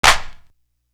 Craze Clap.wav